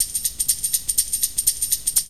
TAMBOU 1  -L.wav